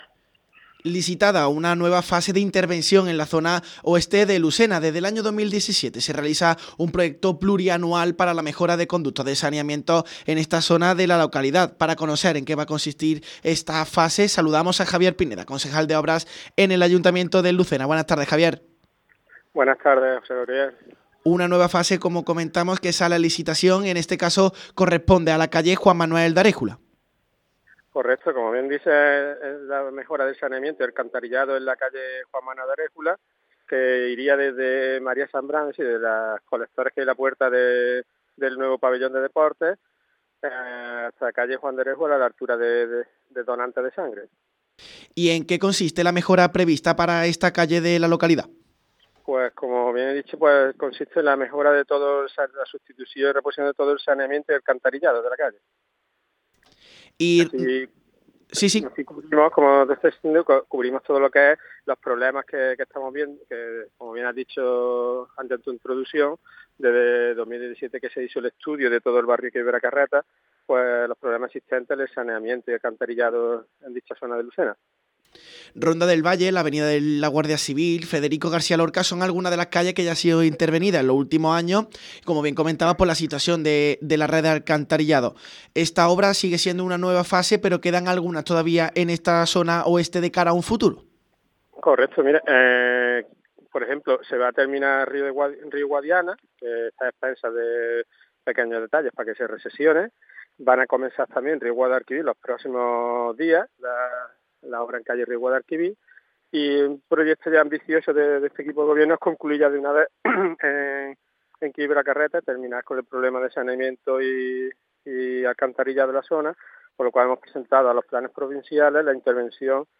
informativos